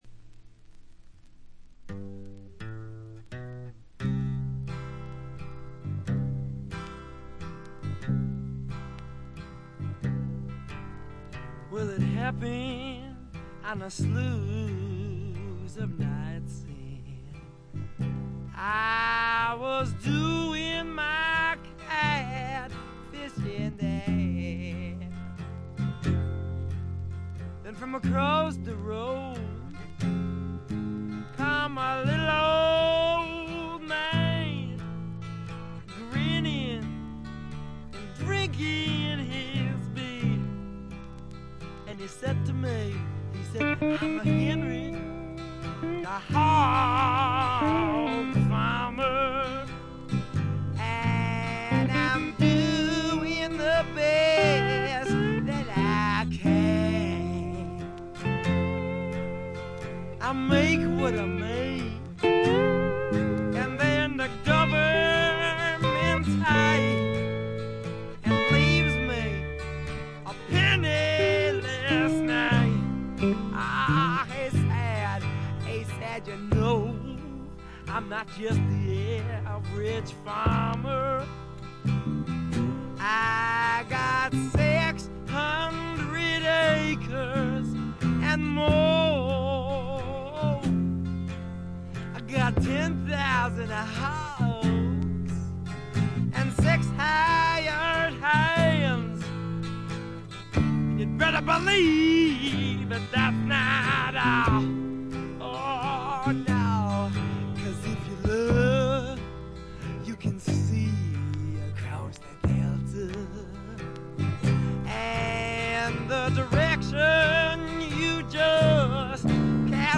軽微なチリプチ少々、散発的なプツ音が少し。
まさしくスワンプロックの理想郷ですね。
試聴曲は現品からの取り込み音源です。
Vocals, Acoustic Guitar, Piano, Violin